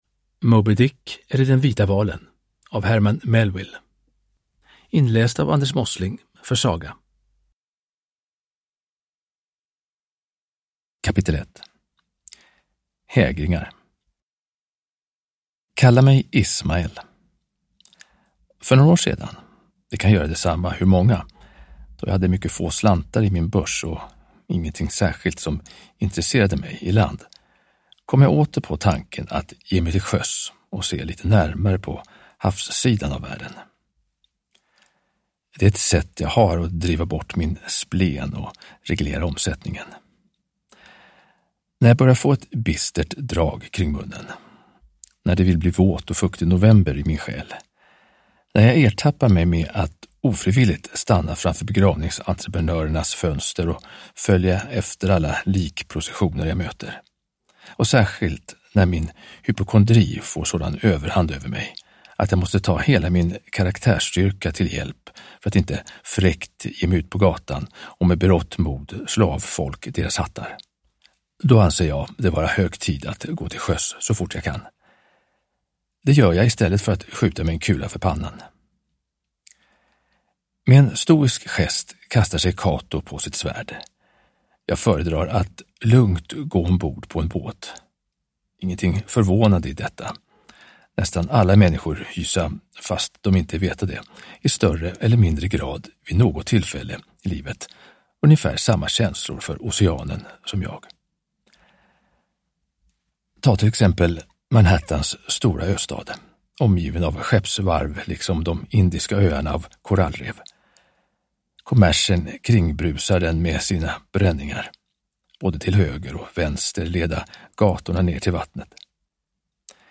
Moby Dick eller den vita valen / Ljudbok